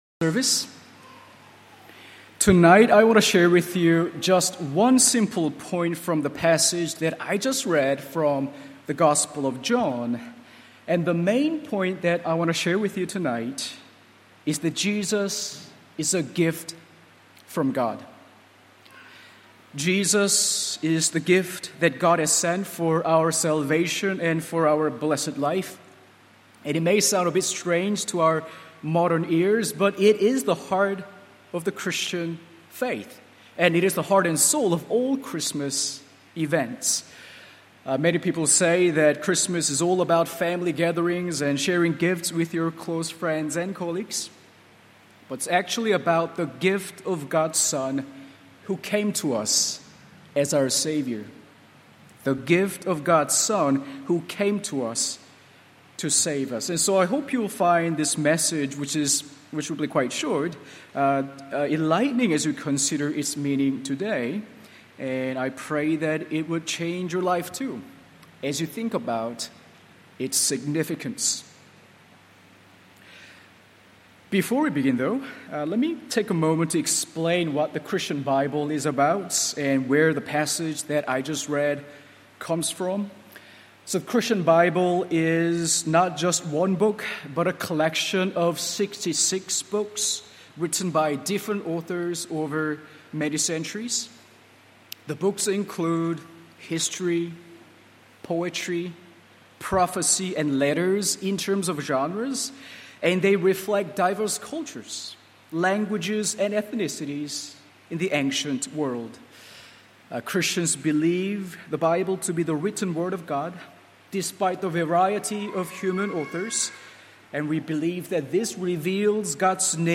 Christmas Carol Service